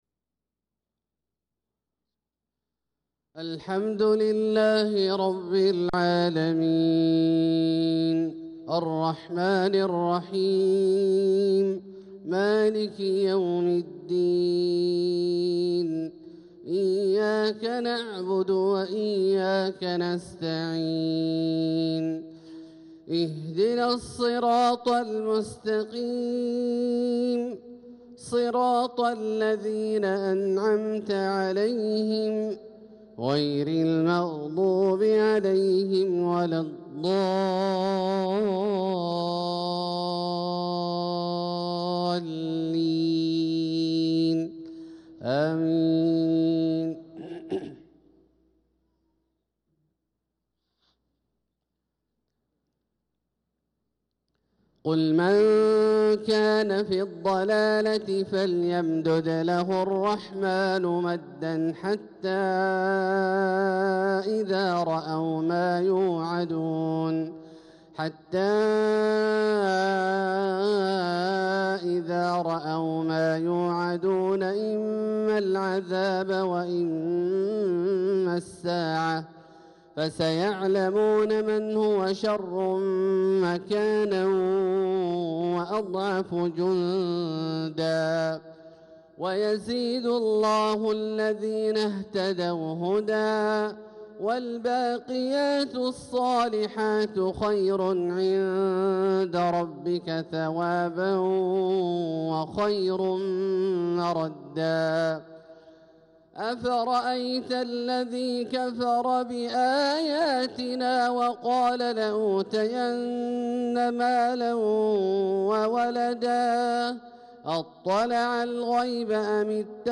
صلاة الفجر للقارئ عبدالله الجهني 18 ذو الحجة 1445 هـ